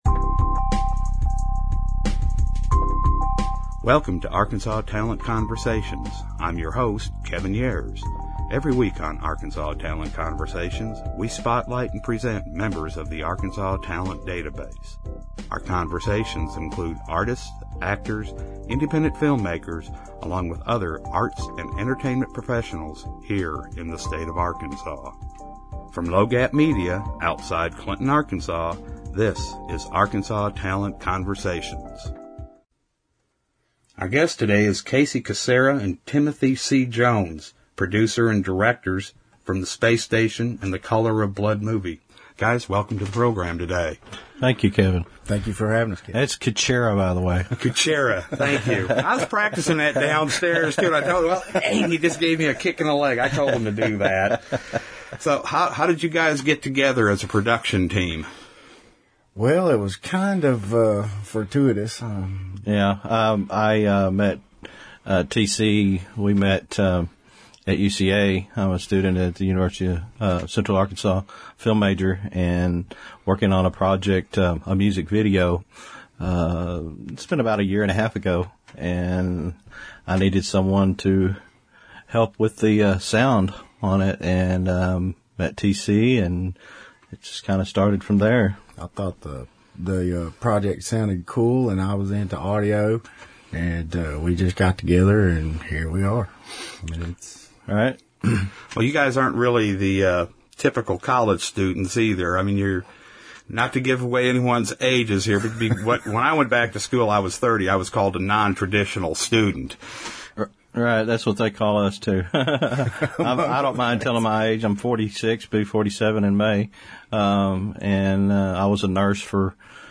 Arkansas Talent Conversations is a weekly podcast that interviews Arts and Entertainment Professionals for perspectives into today's art, film and entertainment scene.